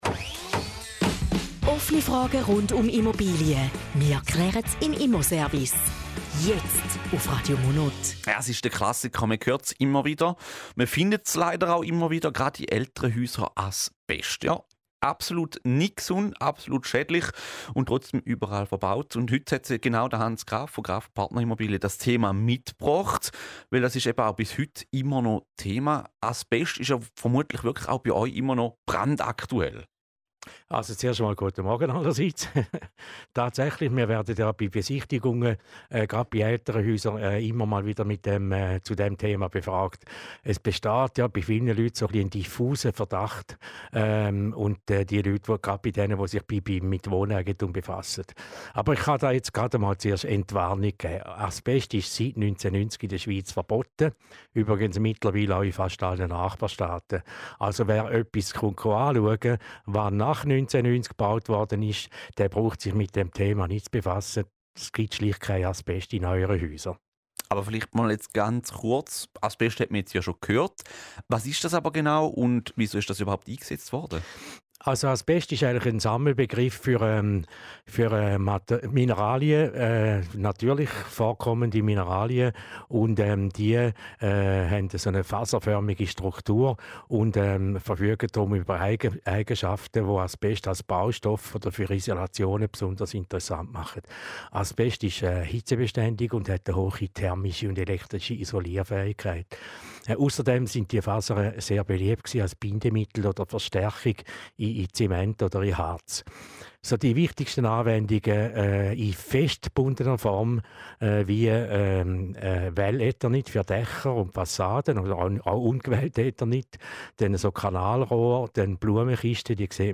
Zusammenfassung des Interviews zum Thema "Abest":